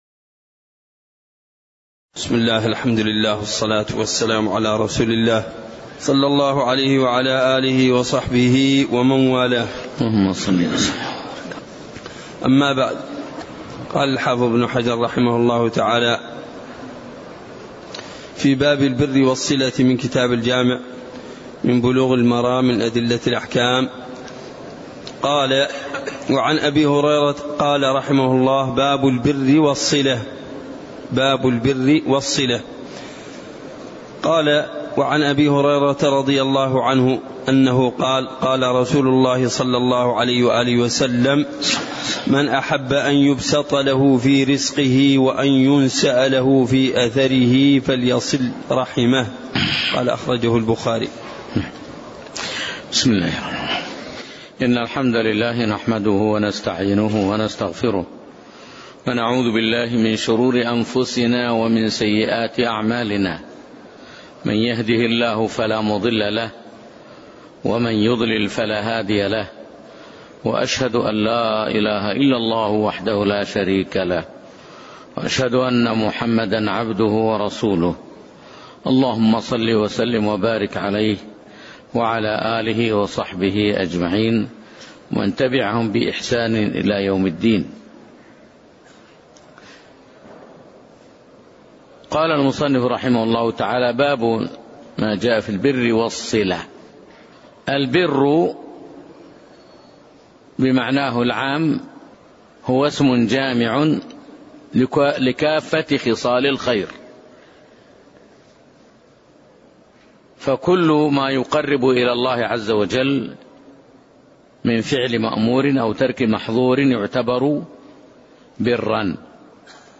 تاريخ النشر ١٢ رجب ١٤٣١ هـ المكان: المسجد النبوي الشيخ